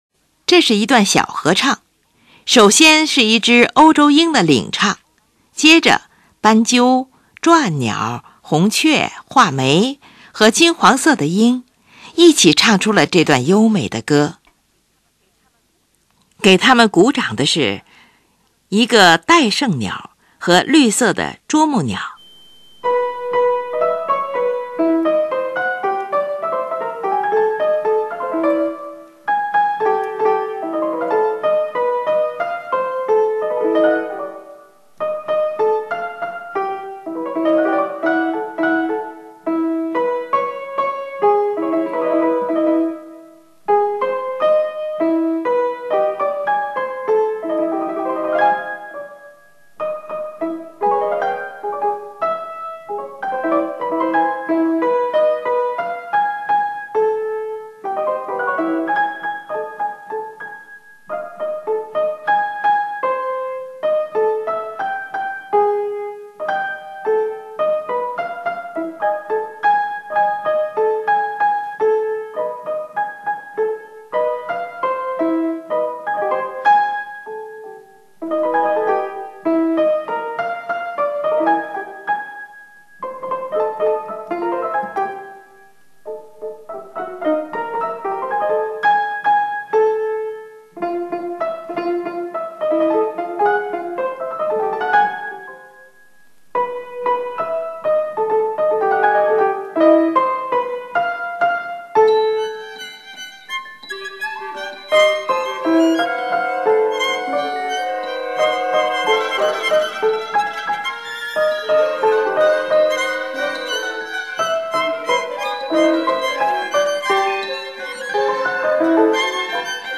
这是一段小合唱。
接着，长笛用颤音表现旁边伴唱的斑鸠的叫声。然后，在钢片琴上奏出啭鸟的啁声，而且单簧管上还有红雀的吱叫。
这的华彩乐段的结尾是知更鸟和黑鸟的二重唱。